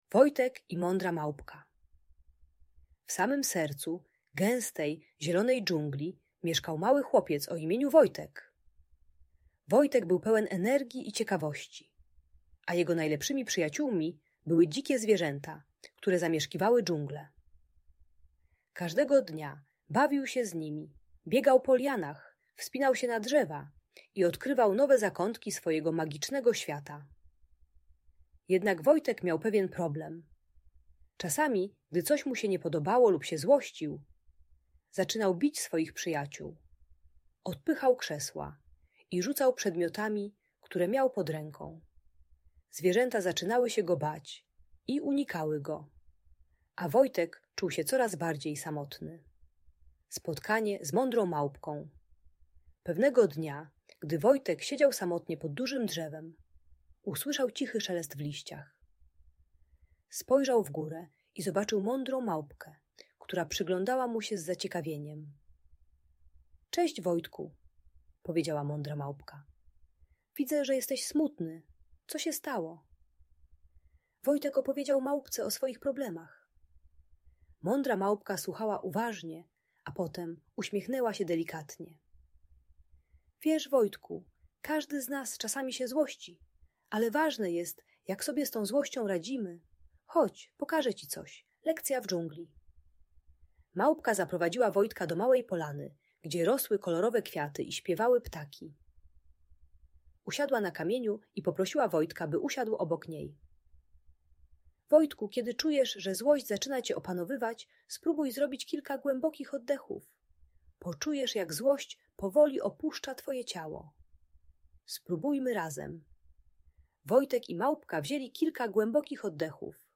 Opowieść o Wojtku i Mądrej Małpce - Audiobajka dla dzieci